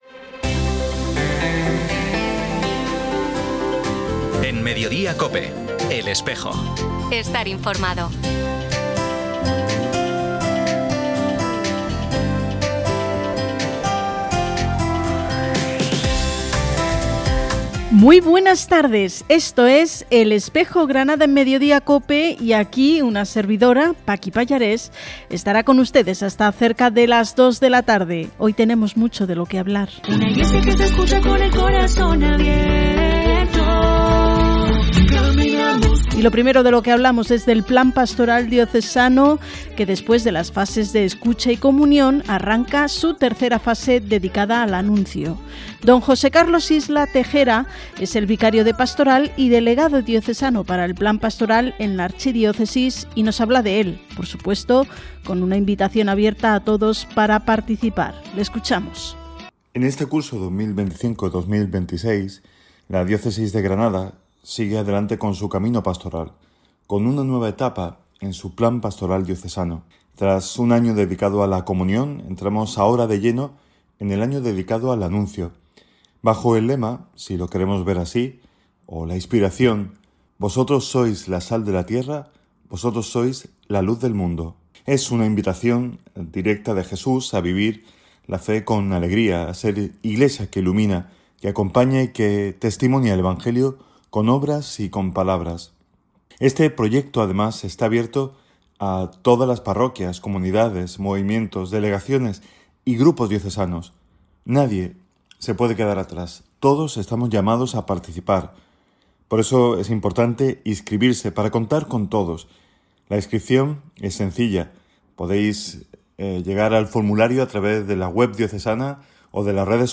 Emitido en COPE Granada el 5 de diciembre de 2025.